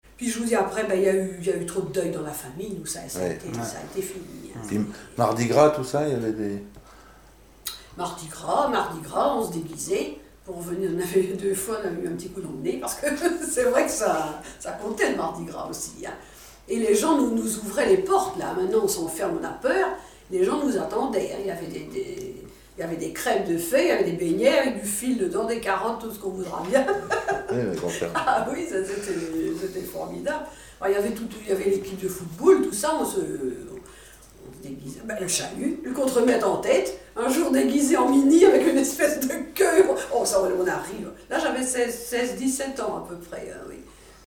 Chansons et commentaires
Catégorie Témoignage